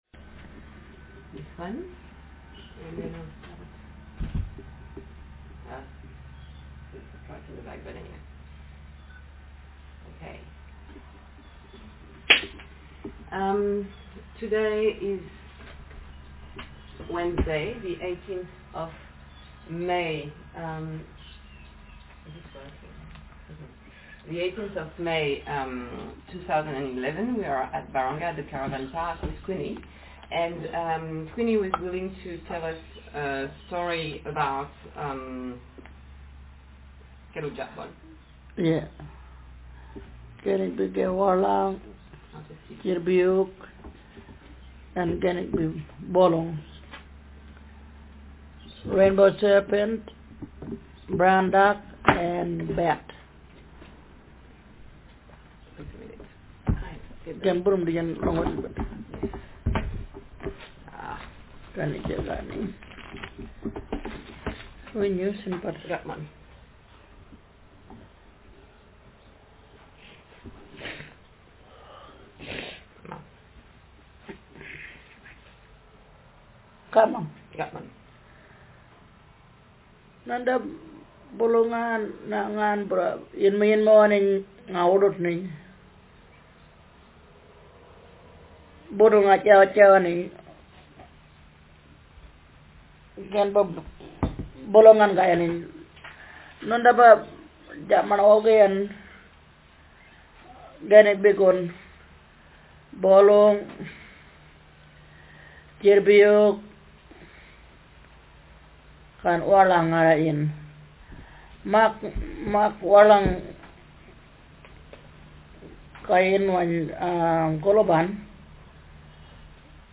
Speaker sex f Text genre traditional narrative